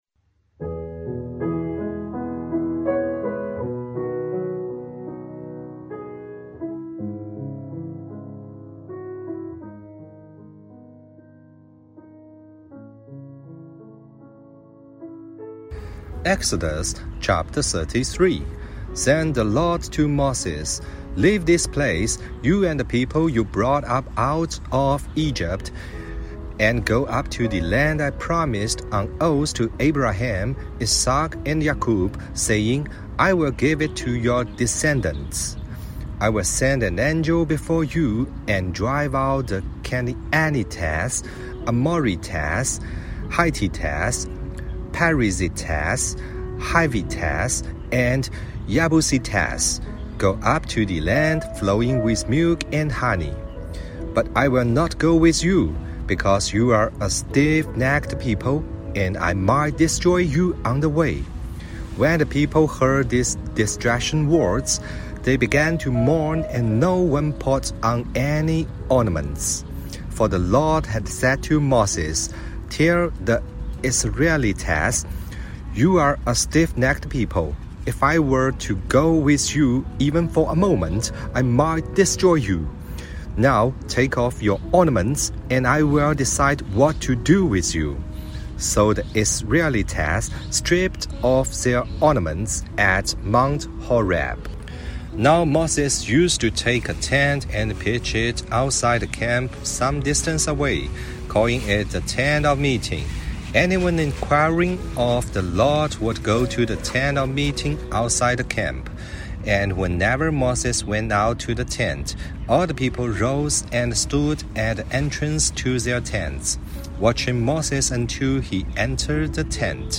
读经马拉松 | 出埃及记33章(英语)